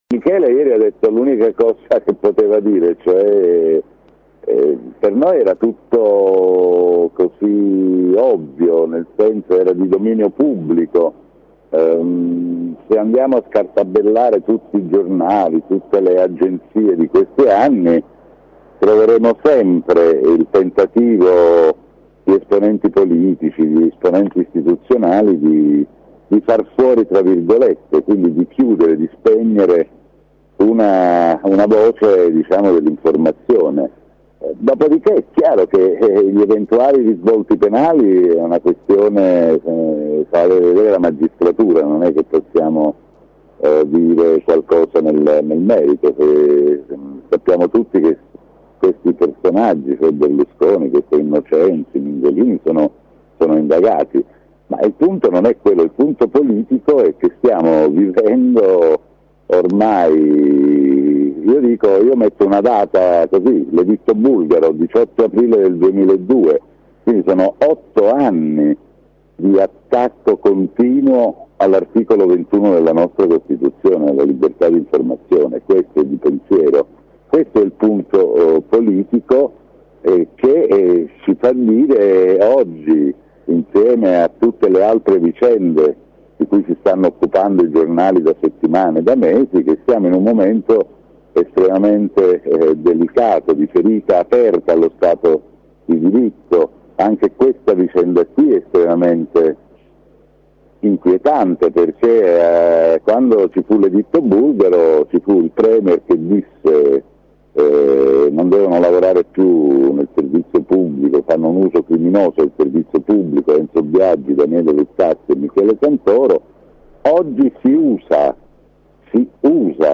Questa mattina il braccio destro di Michele Santoro, Sandro Ruotolo, ha lanciato un appello di sostegno all’iniziativa all’interno di Angolo B.